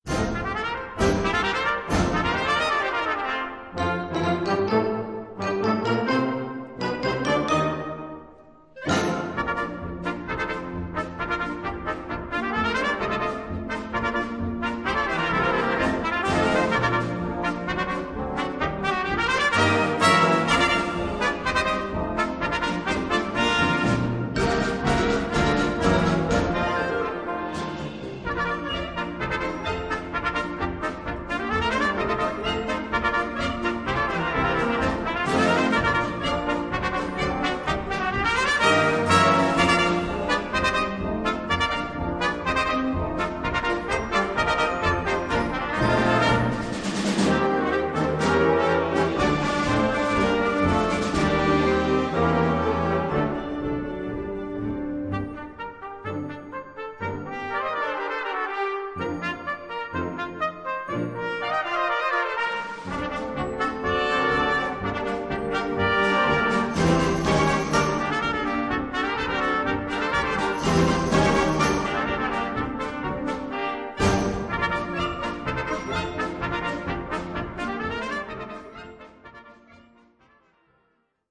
Solo für 3 Trompeten und Blasorchester Schwierigkeit